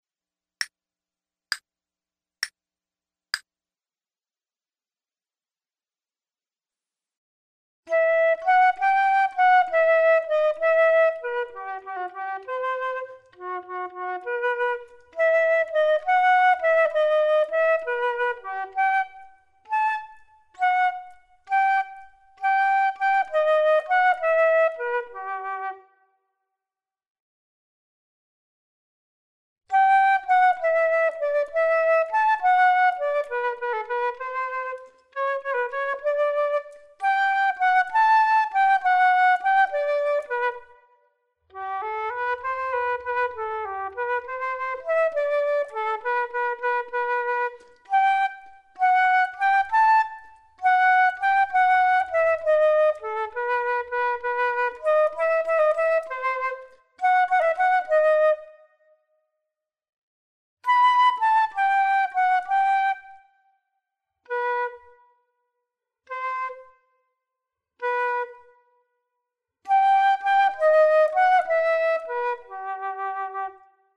Flute 2 Only:
This second movement from Sonata VI, Op.5, by Johann Quantz retains the key of e minor from the first movement but this movement is a lively Allegro.